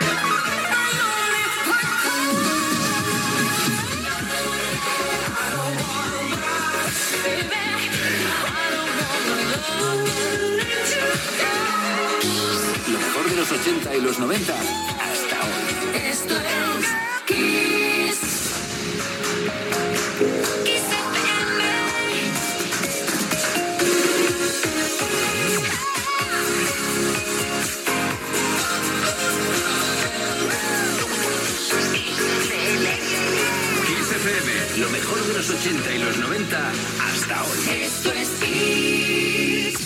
Dos indicatius de l'emissora